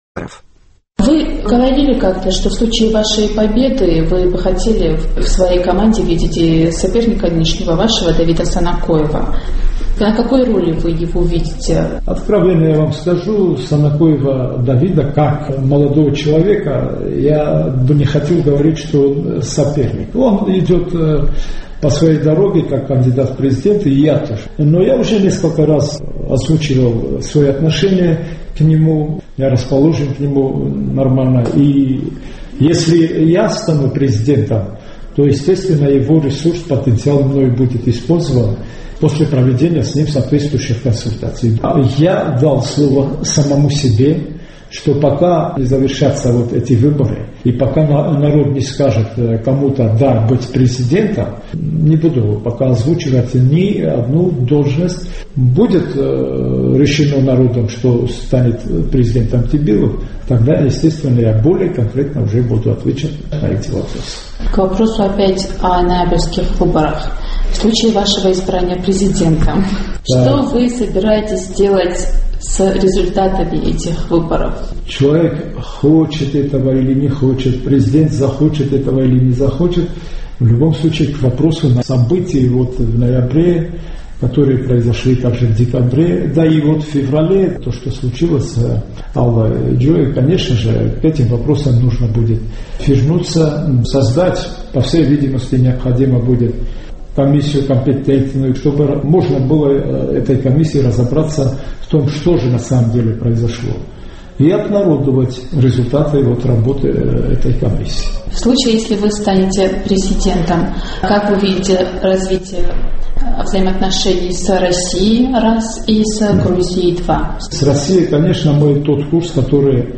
ЦХИНВАЛИ